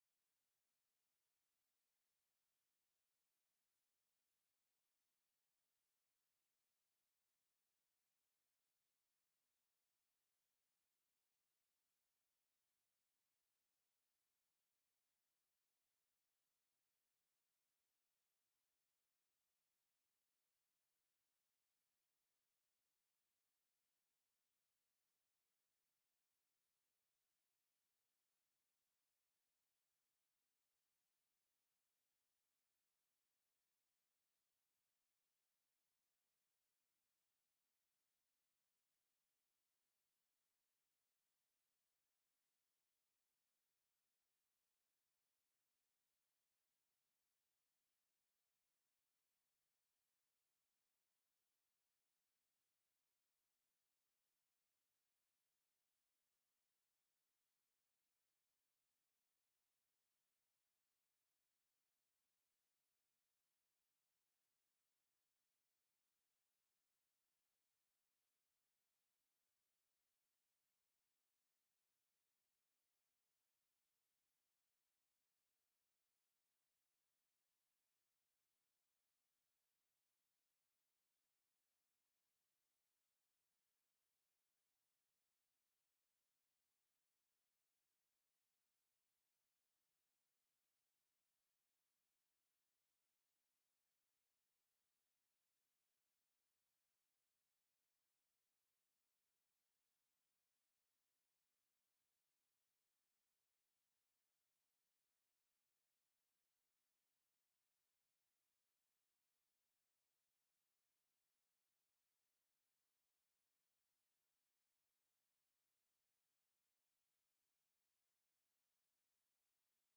04/01/2025 01:30 PM Senate TRANSPORTATION
Presentation: AIDEA Update on Major Projects TELECONFERENCED